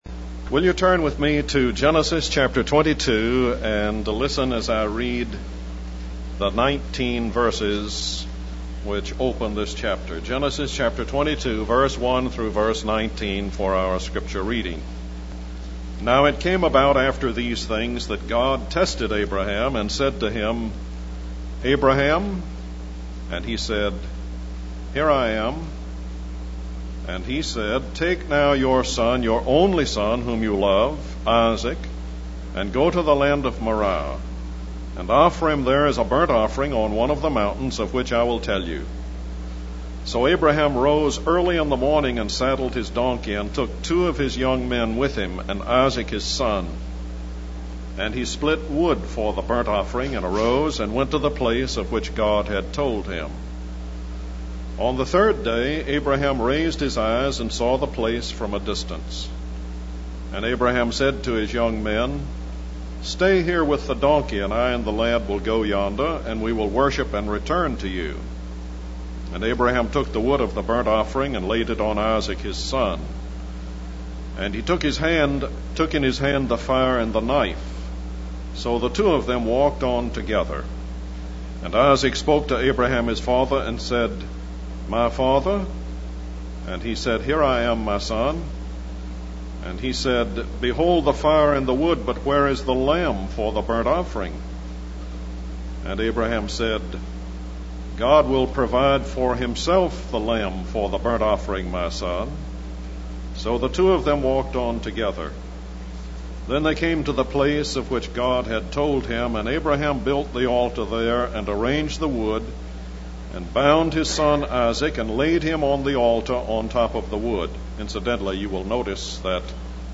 In this sermon, the speaker focuses on Genesis chapter 22 and reads the 19 verses that open the chapter.